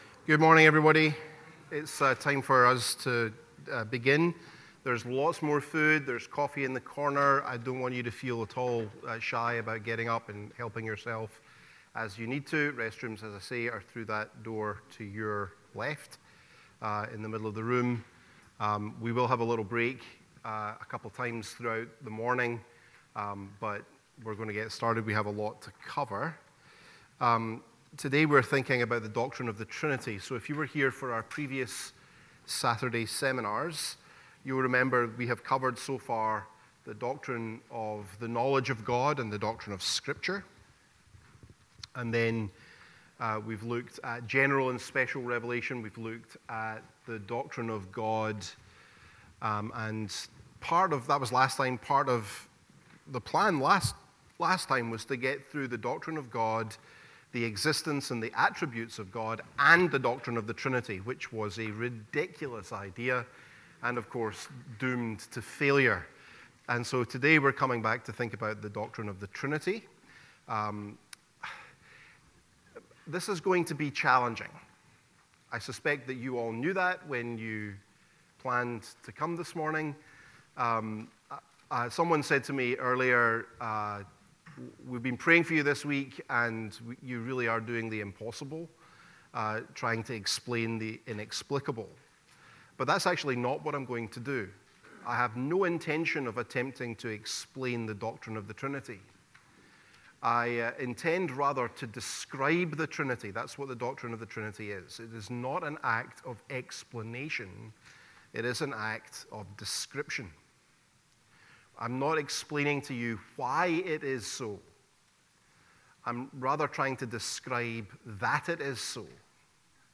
The-Doctrine-of-the-Trinity-Lecture-1-The-Unity-of-God-and-Trinitarian-Vocabulary.mp3